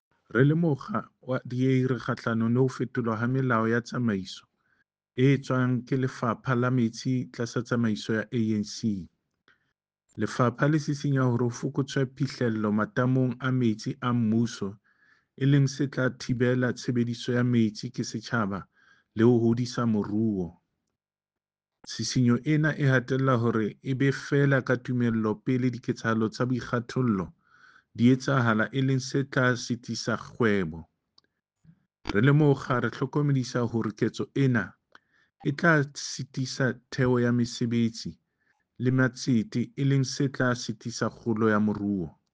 Sesotho soundbite by David Masoeu MPL.